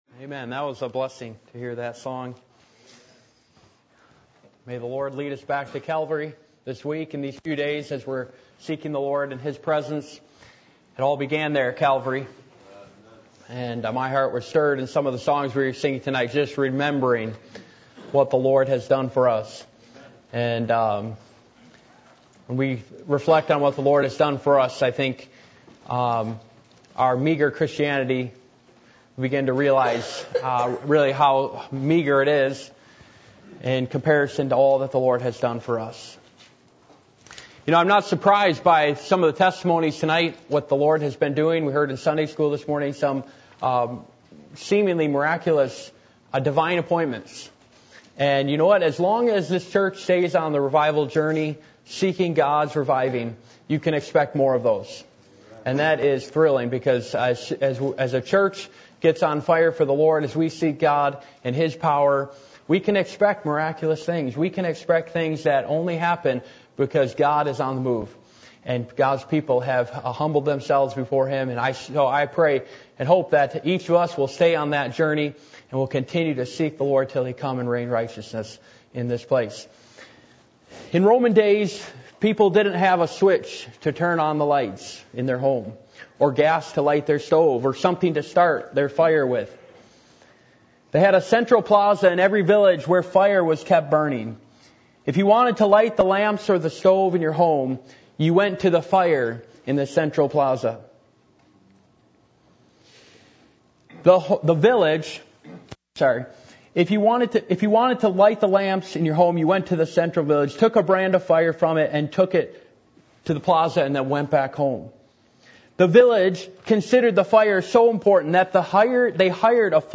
Passage: Revelation 2:1-3:15 Service Type: Sunday Evening